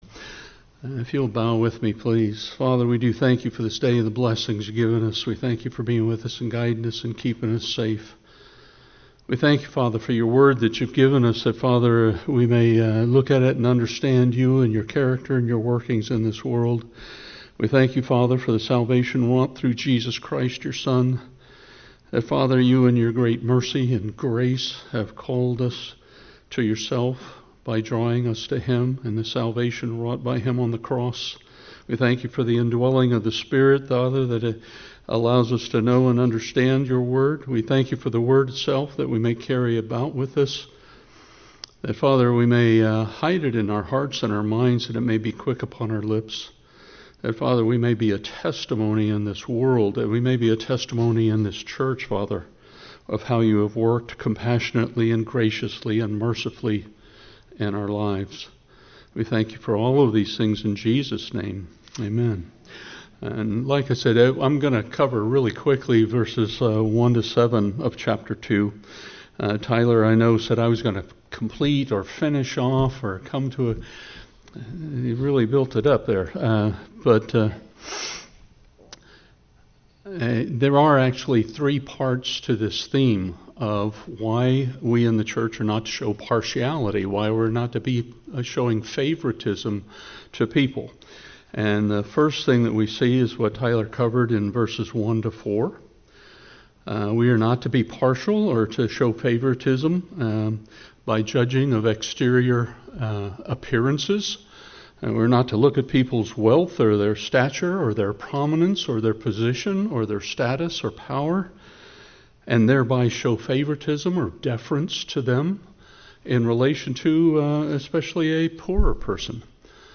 Grace Bible Chapel Non Denominational bible church verse-by-verse teaching